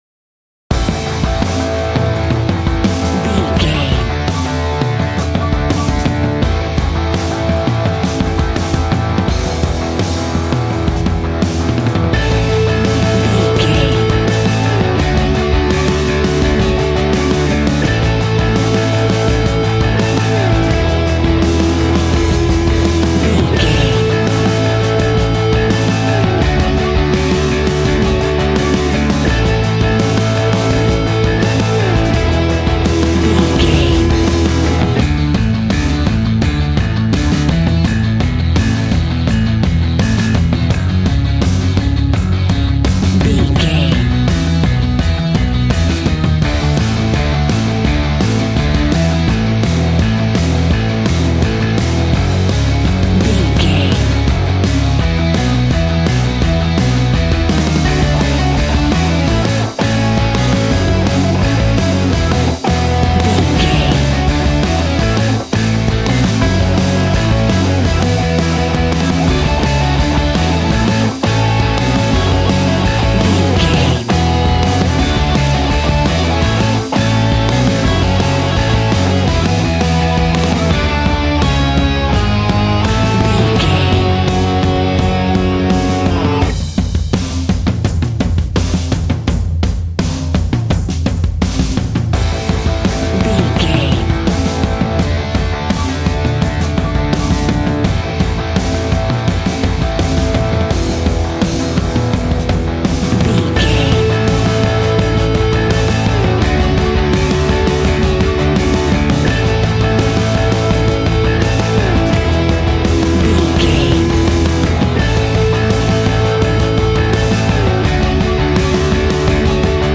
Aeolian/Minor
G♭
groovy
powerful
electric organ
drums
electric guitar
bass guitar